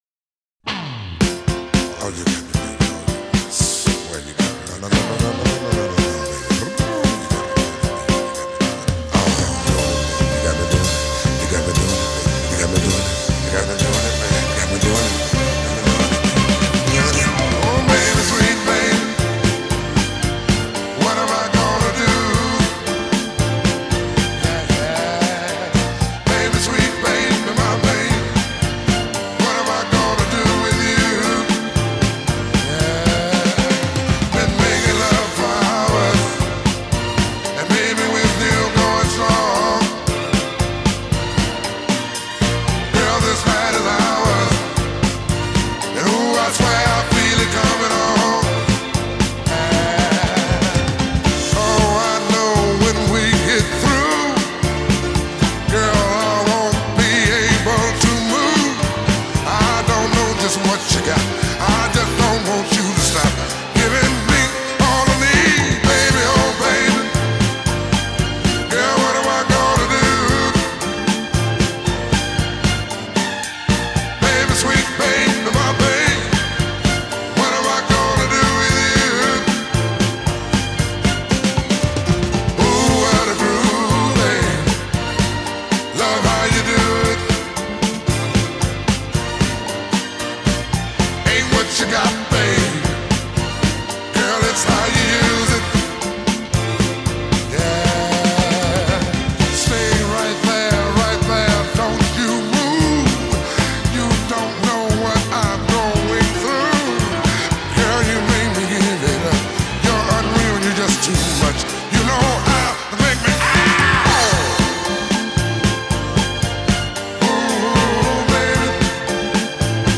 глубокий баритон и откровенно соблазнительная лирика.